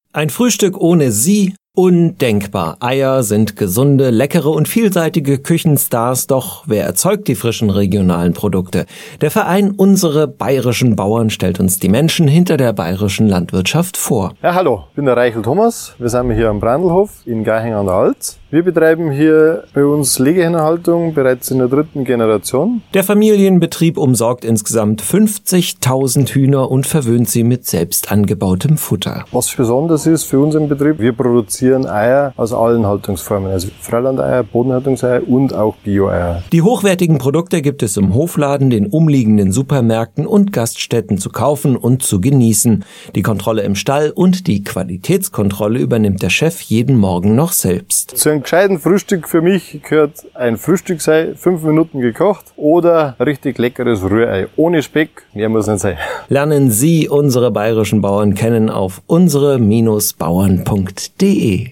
UBB_Radiospot_Eierbauer.mp3